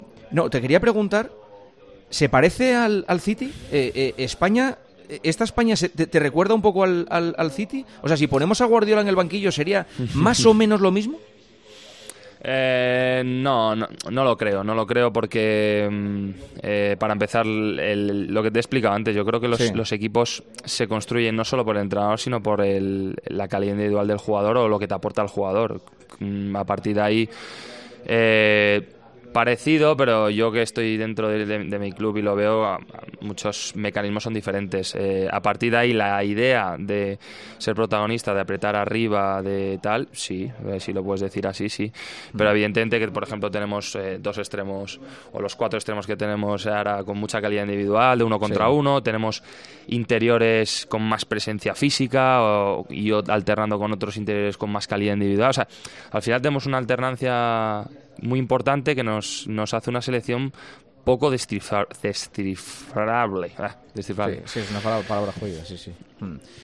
Por eso Juanma Castaño le preguntó a Rodri si Guardiola les entrenase también con el equipo nacional, jugarían así.